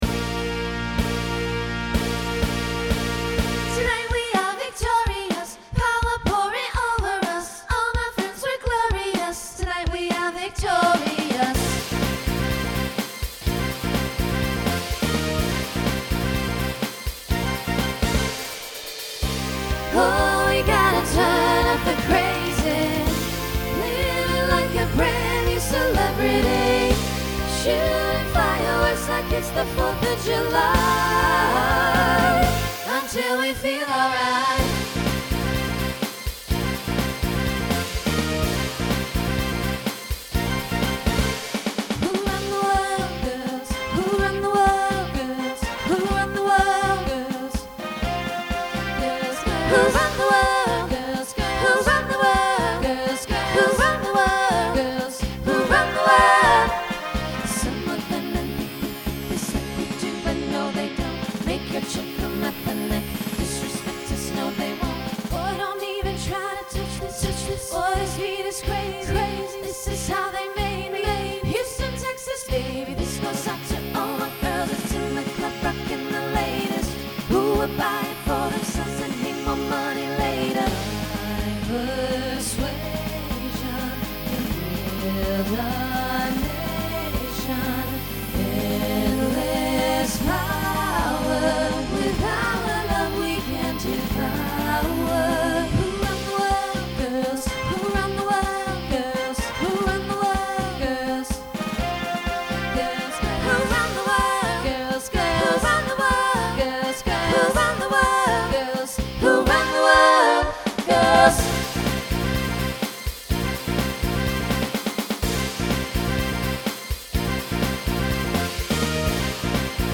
Voicing SSA Instrumental combo Genre Pop/Dance , Rock